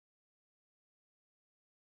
Fail Buzzer — Free Sound Effect Download
Fail Buzzer
Fail Buzzer is a free ui/ux sound effect available for download in MP3 format.
513_fail_buzzer.mp3